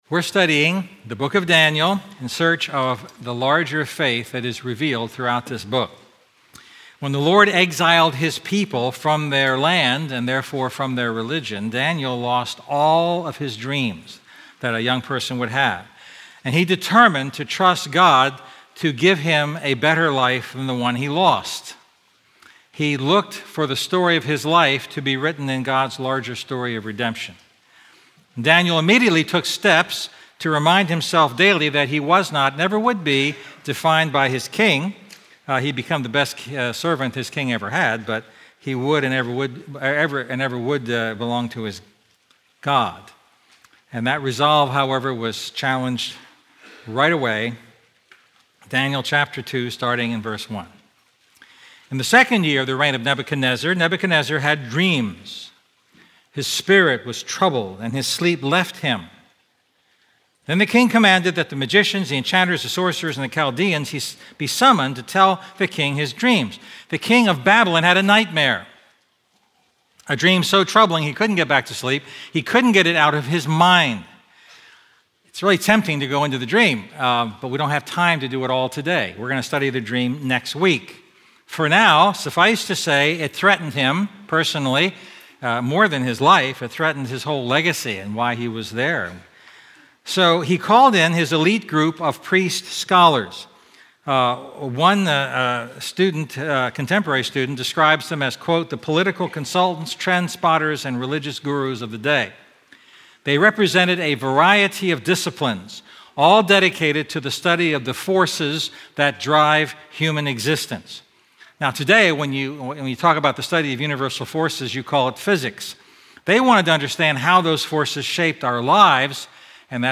A message from the series "A Larger Faith."